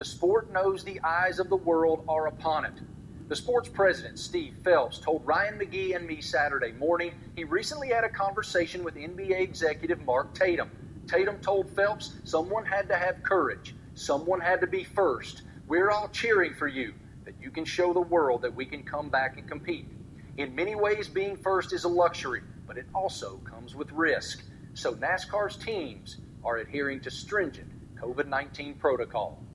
ESPN’s Marty Smith spoke about other league’s executives were watching how NASCAR handled the race Sunday.